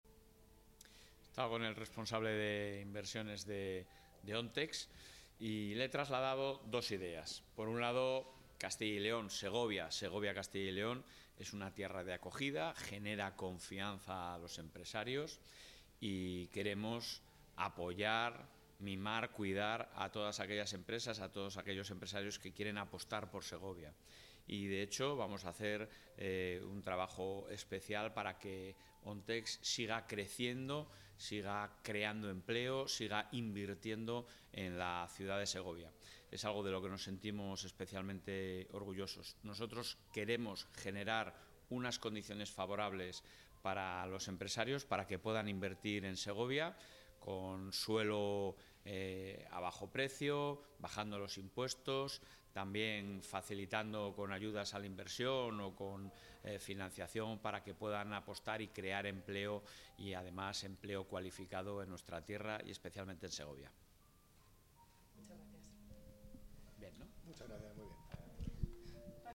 Declaraciones del presidente de la Junta.